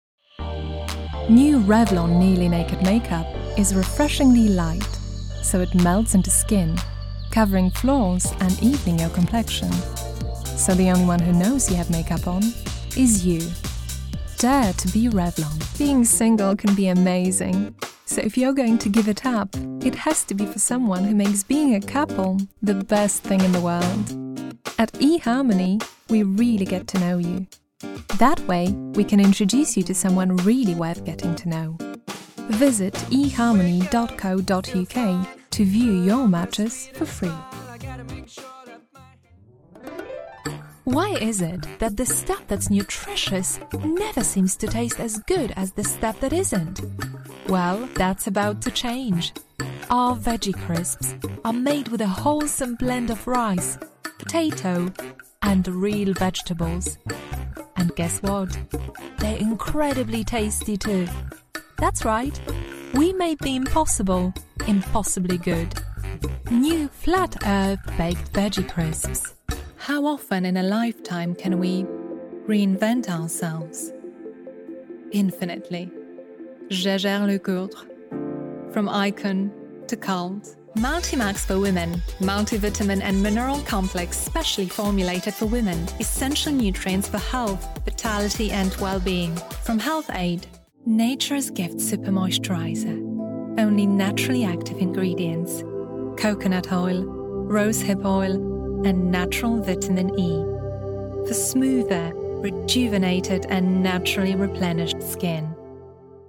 Kommerzielle Demo
RODE NT1A, komplett isolierte Sprecherkabine im Schrank. Scarlett 2i2 Interface, MacBook Air, Adobe Audition
Mezzosopran
SchwülFreundlichKonversationZuverlässigVielseitigFesselnd